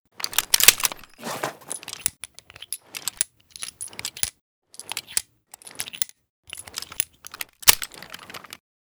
mp412_reload.ogg